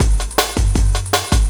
06 LOOP05 -L.wav